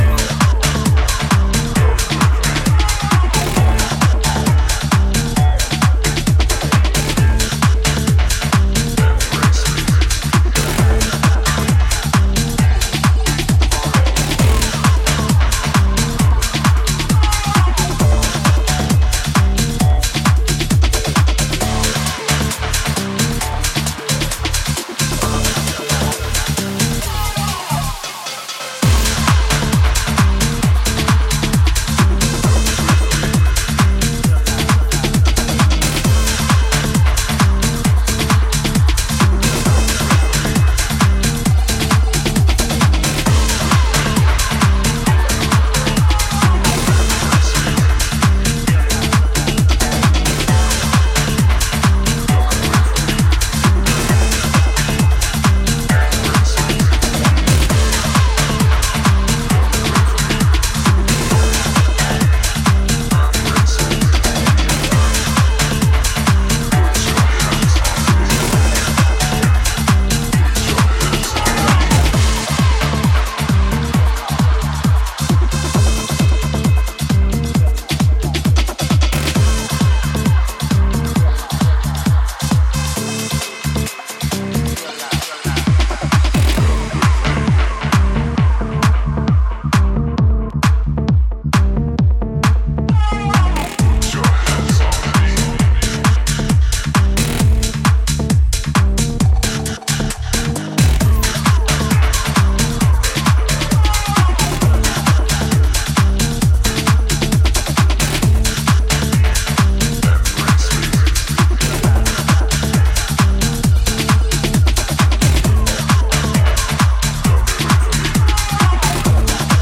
prog house